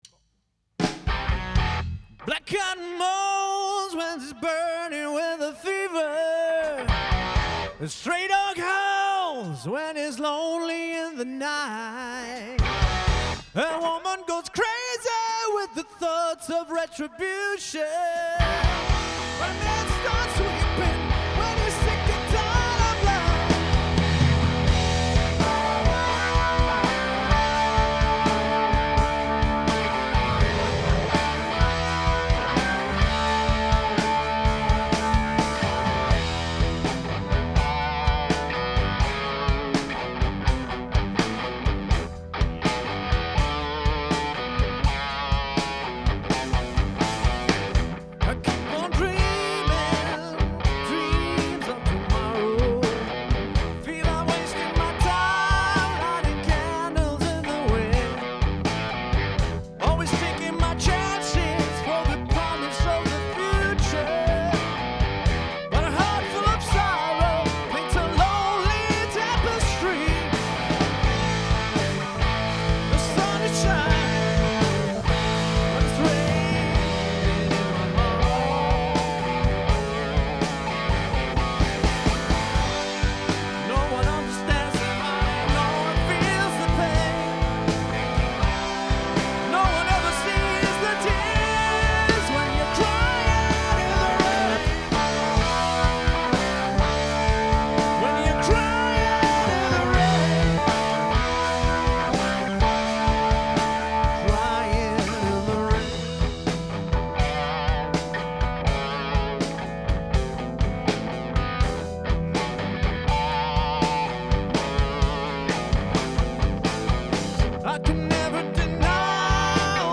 128 bpm